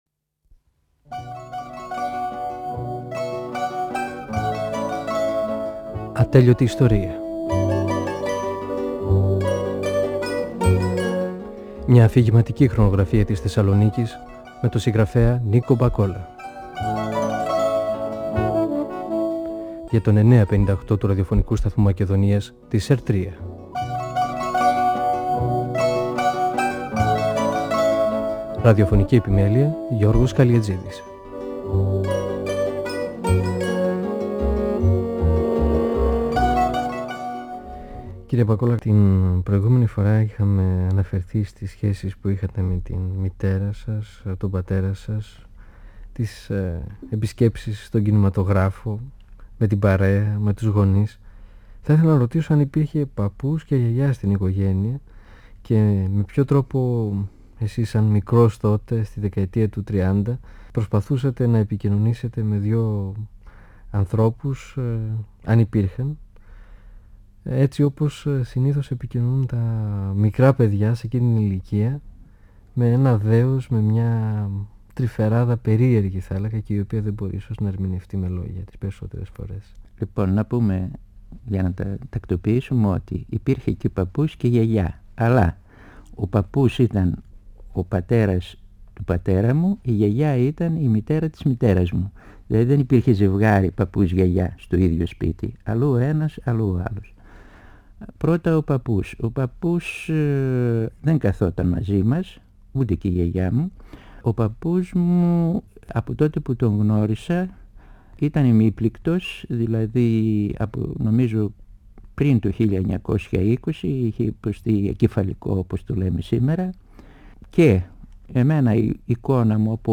(Εκπομπή 3η) Ο πεζογράφος Νίκος Μπακόλας (1927-1999) μιλά για τα παιδικά του χρόνια στη γειτονιά του, την περιοχή της οδού 25ης Μαρτίου.